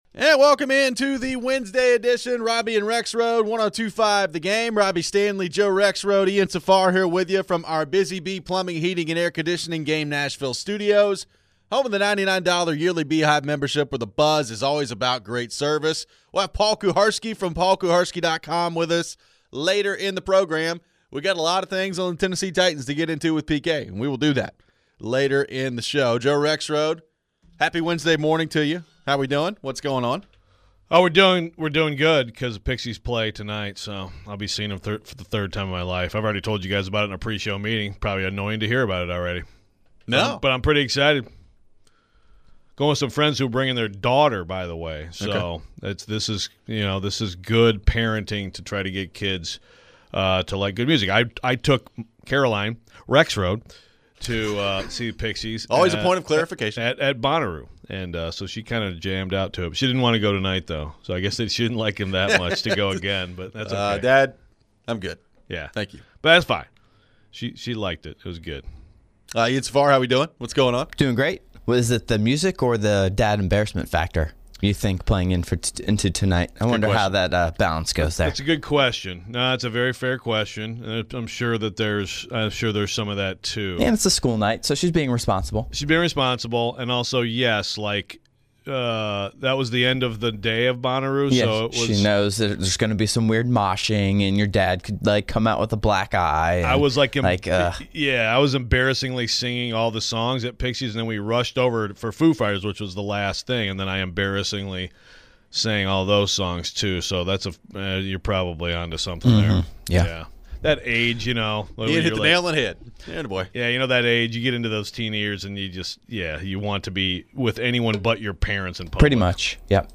Should it matter what Ward thinks? We take your phone calls.